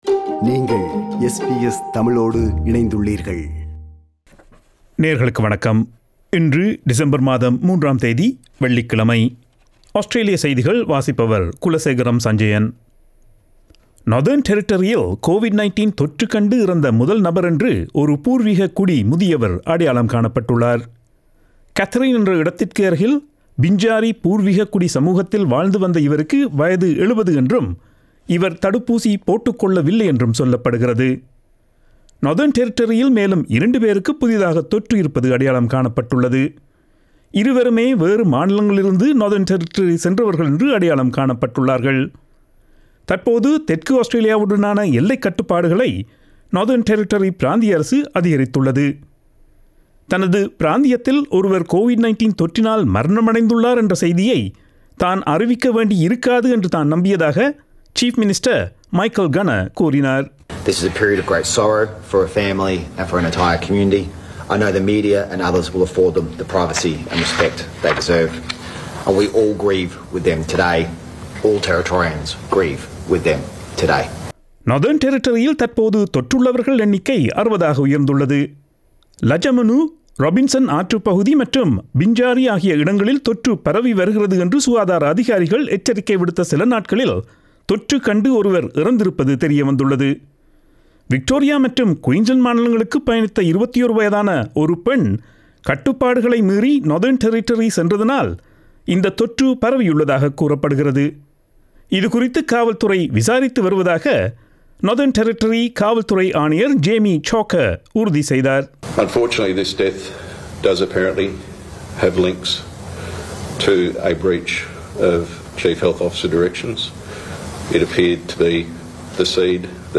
Australian news bulletin for Friday 03 December 2021.